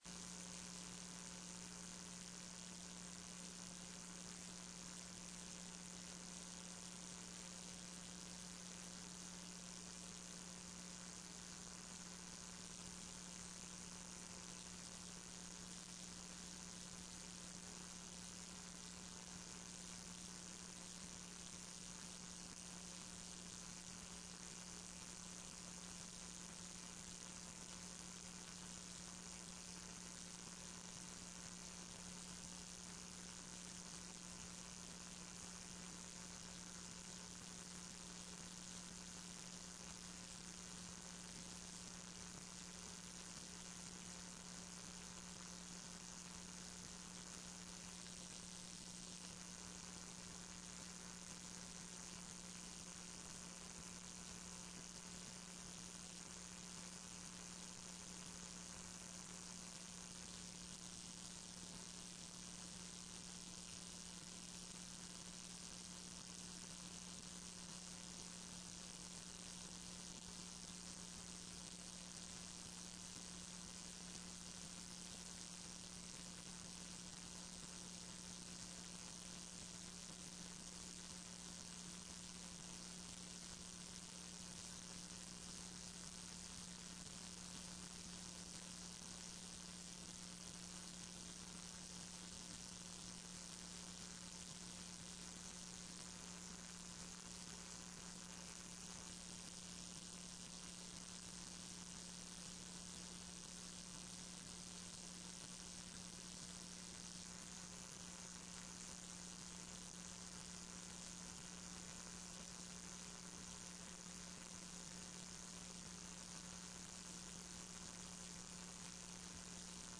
TRE-ES - Áudio da sessão 29.10